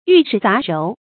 玉石雜糅 注音： ㄧㄩˋ ㄕㄧˊ ㄗㄚˊ ㄖㄡˊ 讀音讀法： 意思解釋： 比喻好壞混雜。